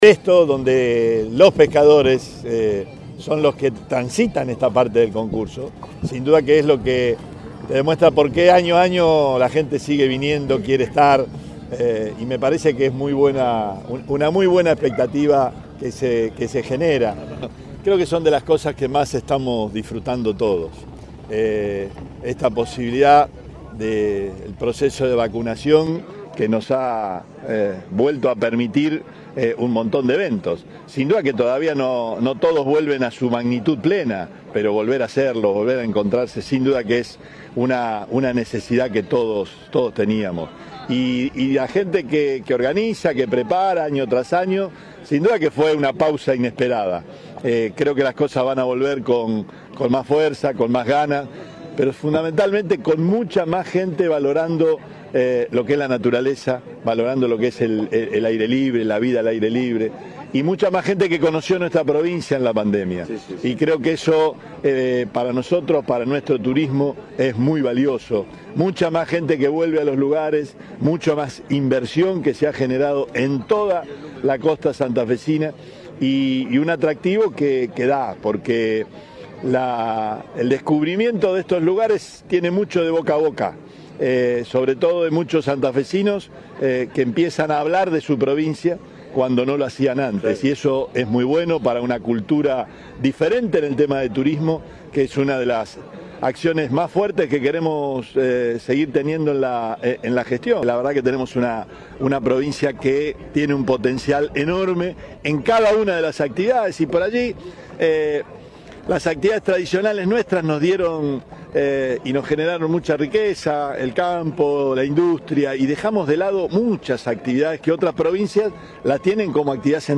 Por la mañana de este sábado, el gobernador participó de la largada de la 35° edición del Concurso Argentino de Pesca del Surubí, que se desarrolla en zona aguas arriba del Puerto Reconquista.
Perotti en la 35° edición del Concurso Argentino de Pesca del Surubí.